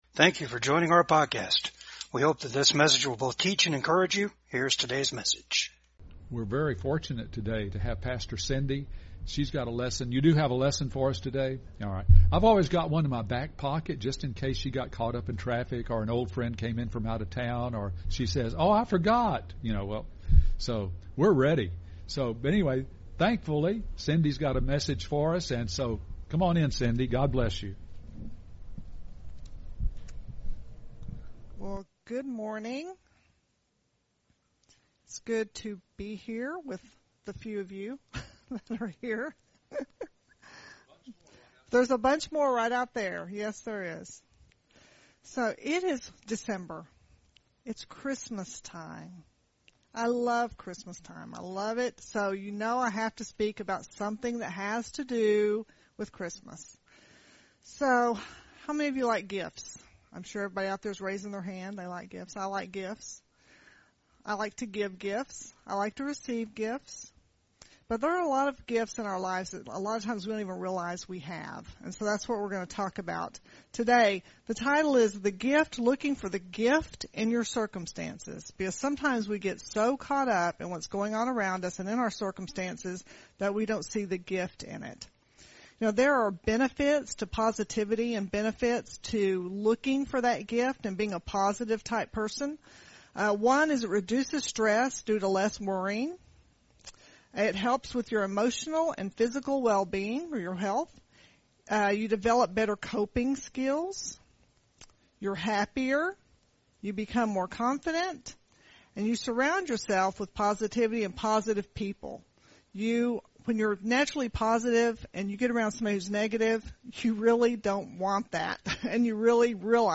15-16 Service Type: VCAG WEDNESDAY SERVICE GOD PUTS POSITIVE THINGS